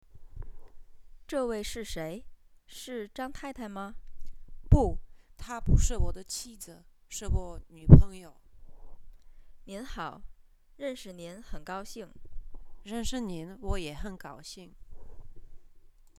L3_Konverzace_II.mp3